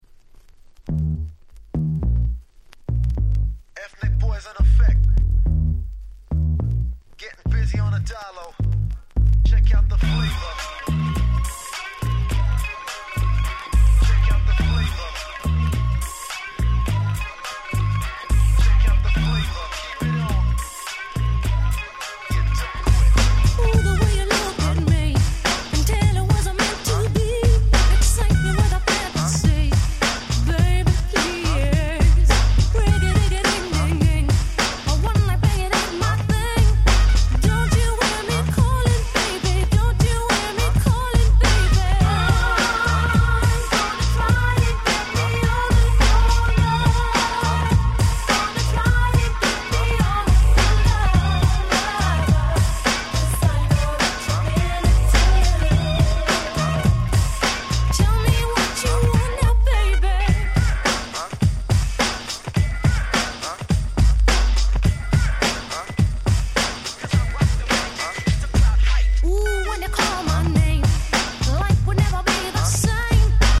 94' Nice UK R&B !!